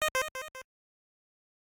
chat-alert.ogg